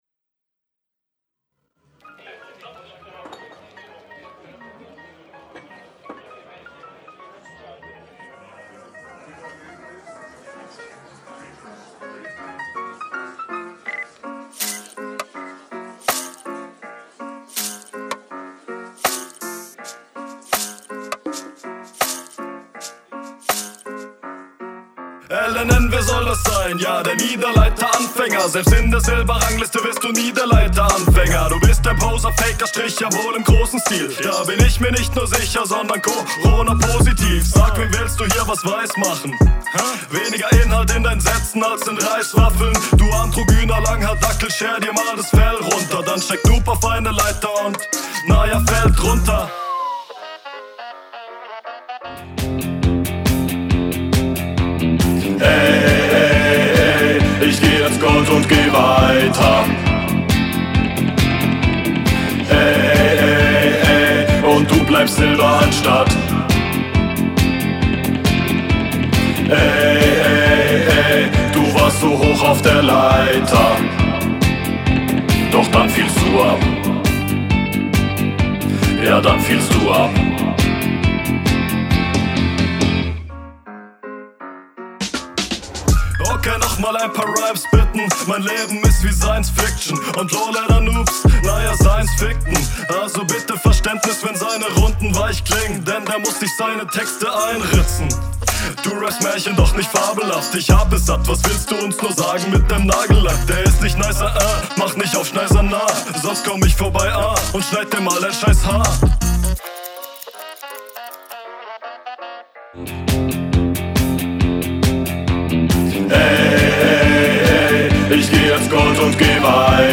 Hast nen coolen Flow Kann man machen Runter auf der Leiter war okay Der Rest …
nicht der beste text aber die hook mag ich und der rap klingt gut